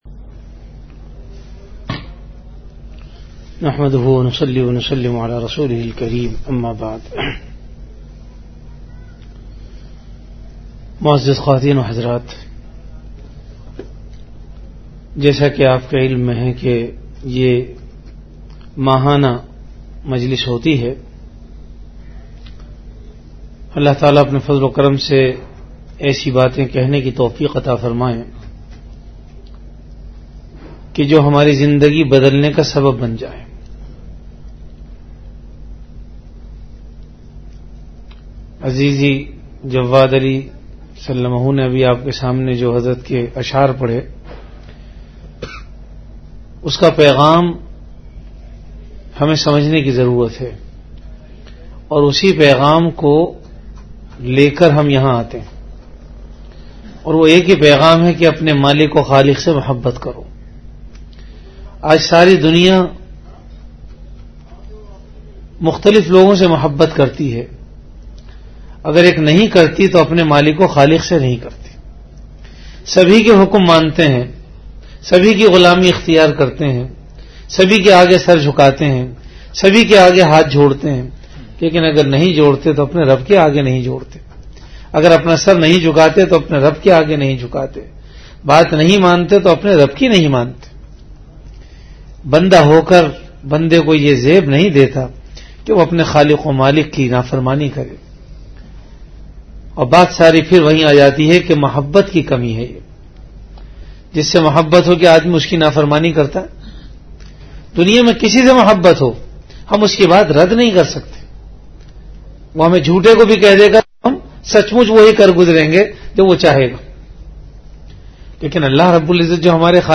Delivered at Home.
Bayanat